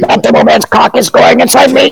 soldier_cloakedspyidentify05.mp3